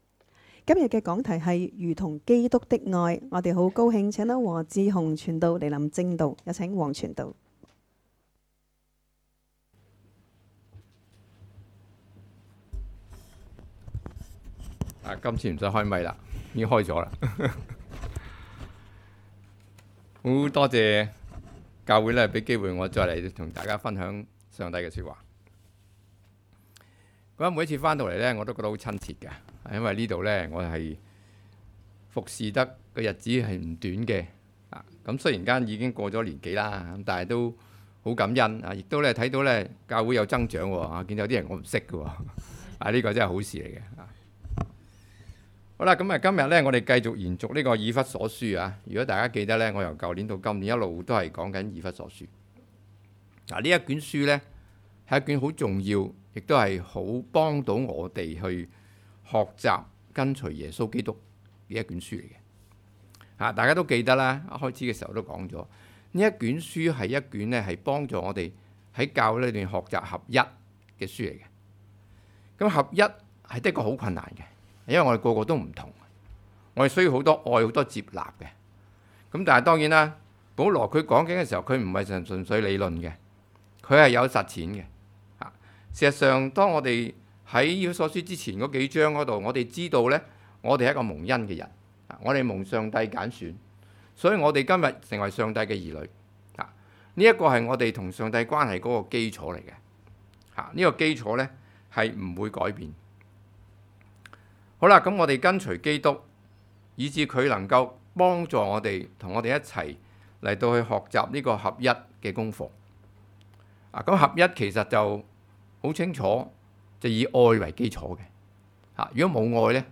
講道 ： 如同基督的愛